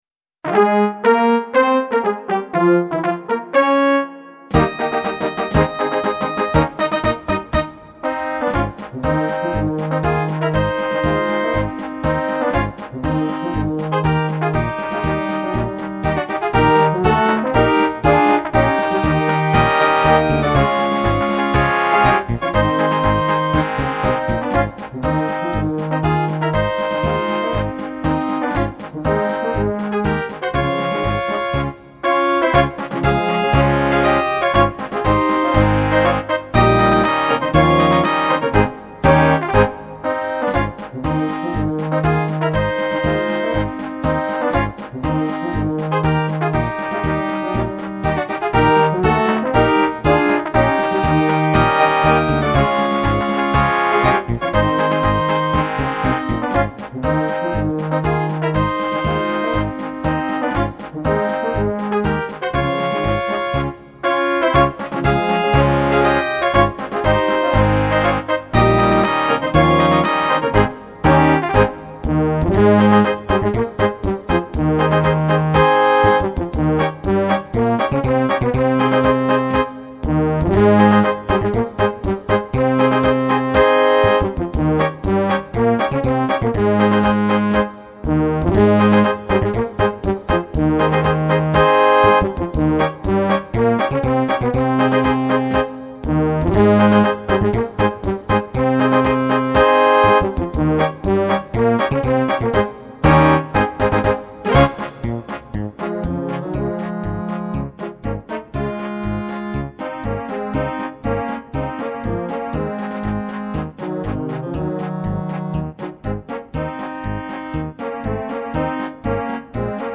Marsch